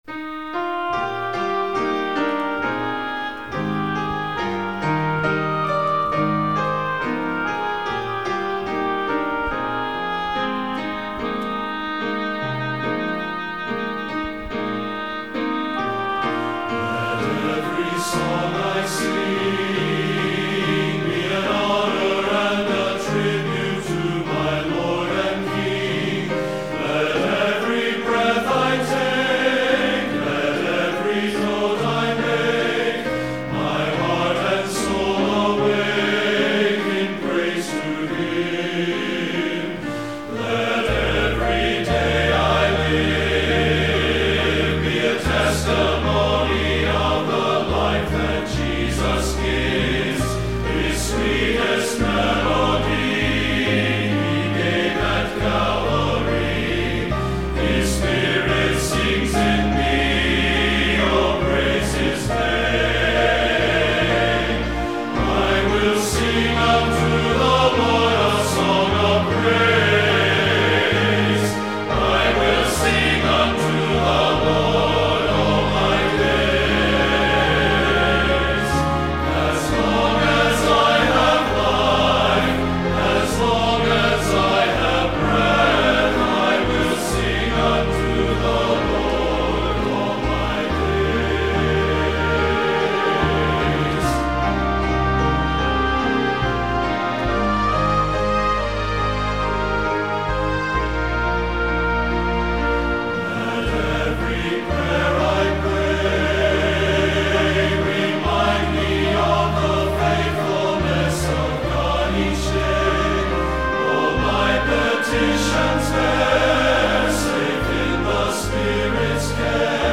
TTBB
Listening Demo, Sheet Music